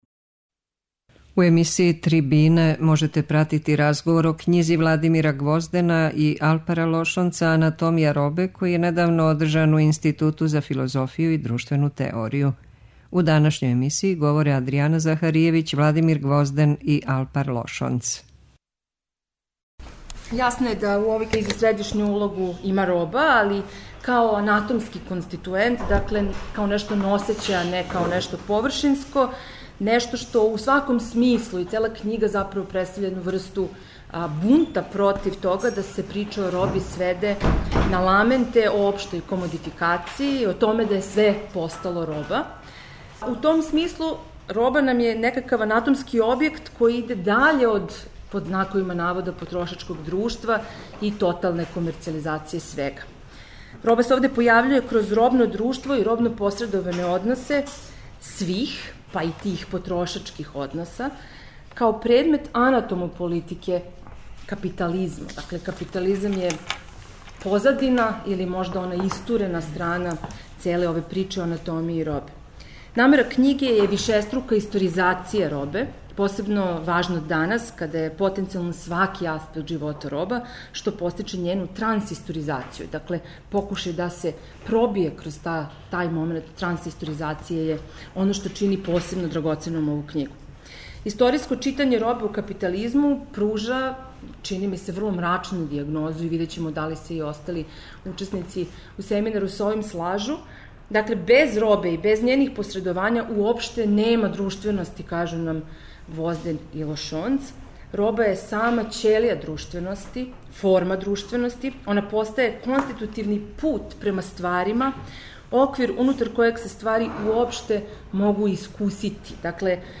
У разговору о књизи, поред самих аутора, учествује и група новосадских и београдских филозофа и теоретичари углавном млађе и средње генерације.
преузми : 13.53 MB Трибине и Научни скупови Autor: Редакција Преносимо излагања са научних конференција и трибина.